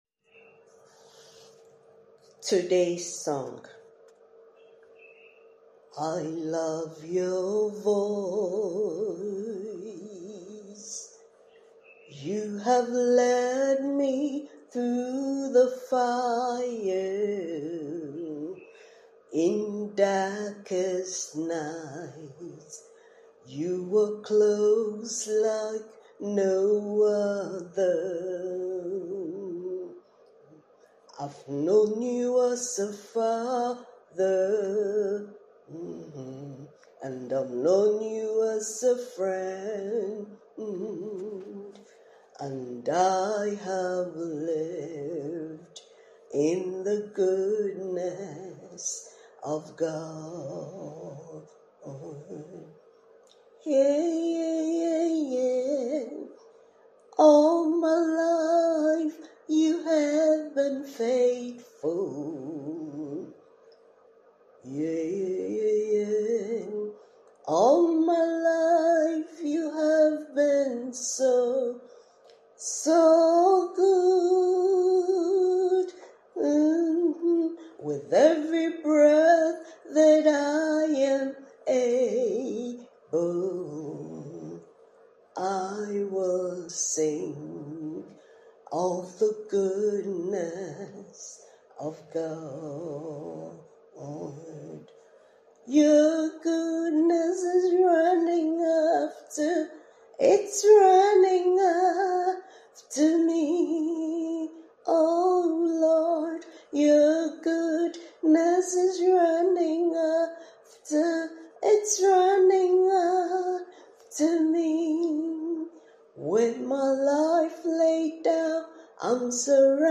Song for meditation